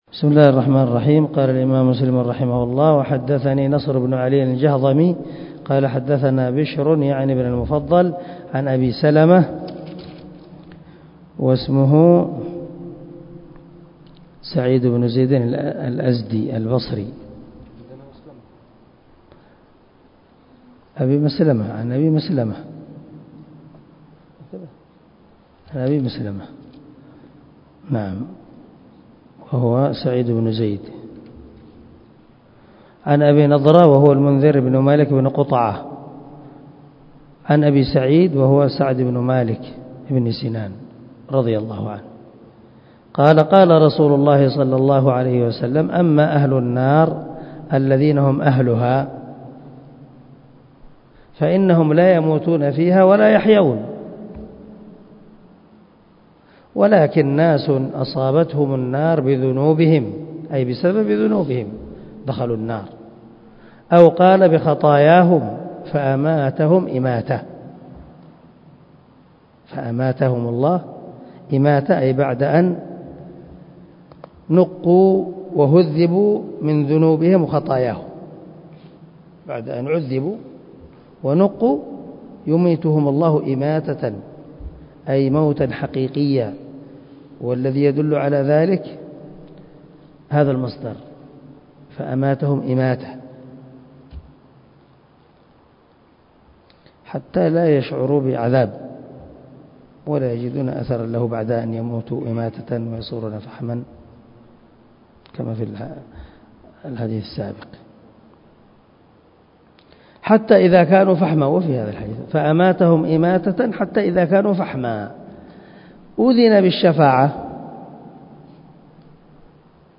145الدرس 144 من شرح كتاب الإيمان حديث رقم ( 185 ) من صحيح مسلم